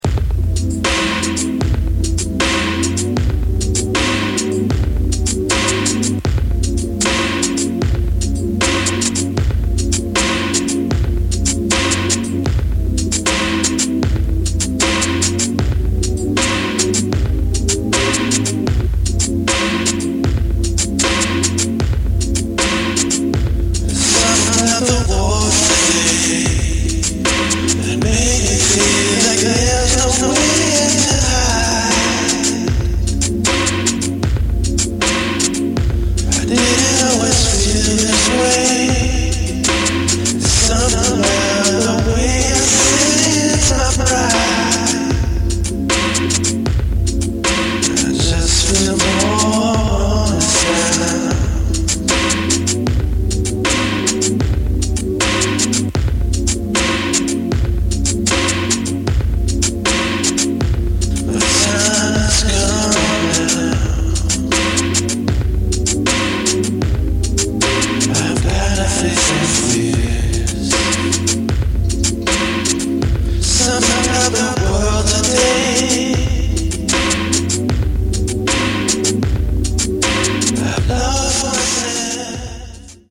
blue-eyed soul